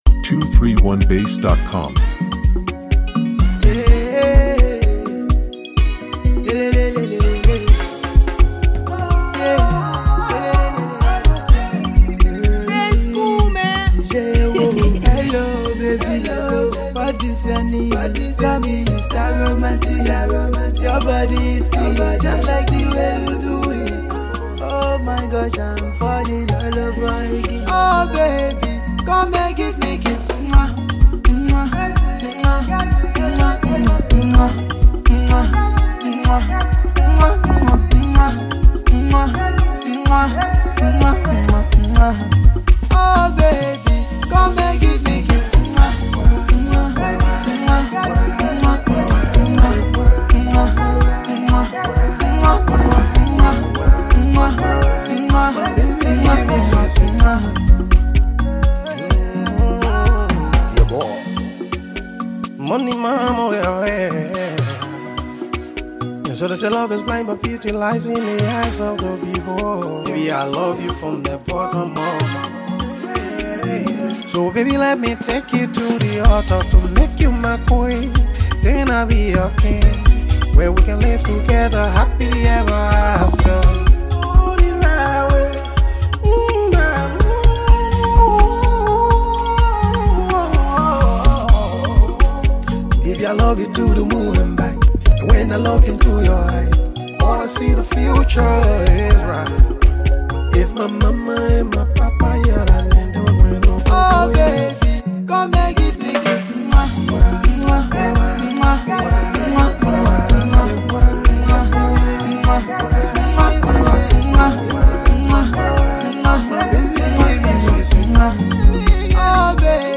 This one is a sweet serenade perfect for the love season.